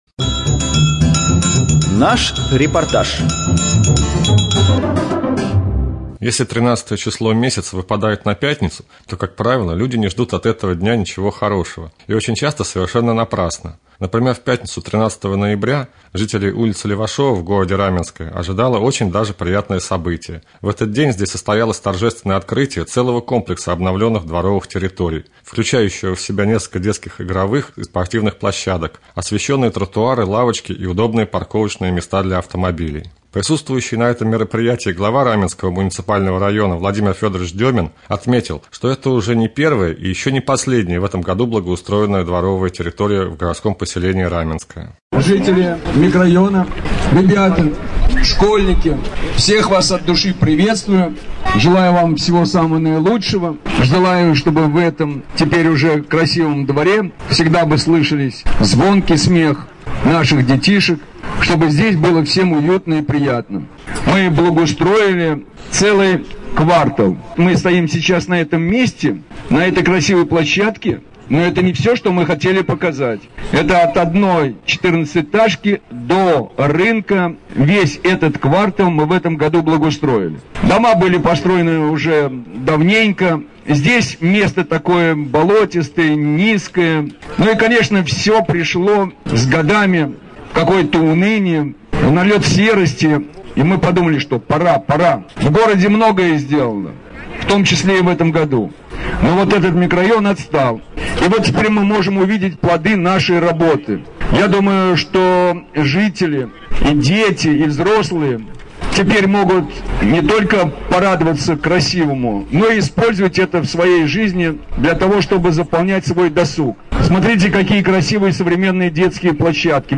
3.Рубрика «Специальный репортаж». На ул.Левашова состоялось торжественное открытие комплекса обновленных дворовых территорий.